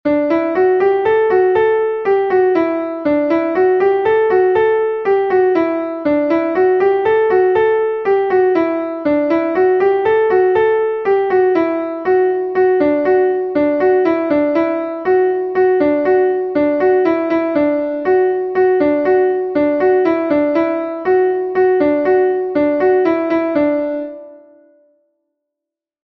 Ridée Pevar Den II est un Laridé de Bretagne enregistré 1 fois par Pevar Den